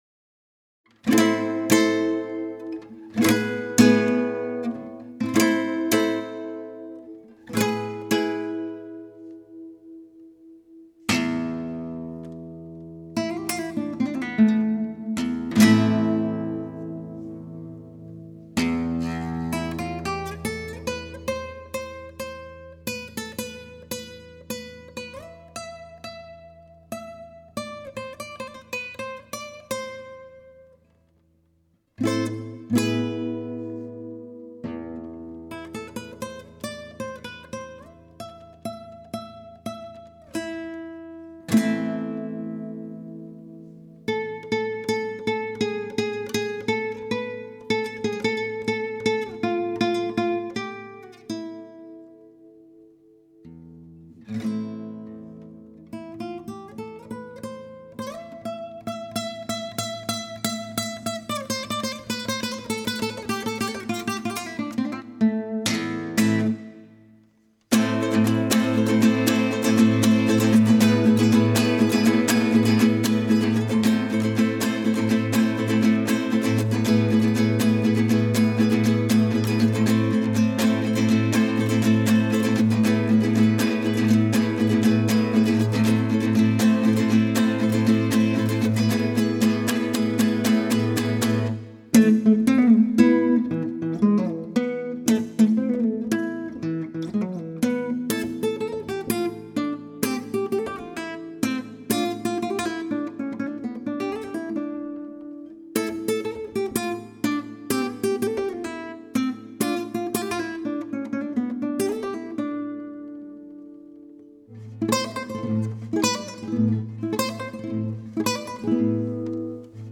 It's been a long while since I have practiced my classical guitar.
I don't know, it just sounds not very good to me.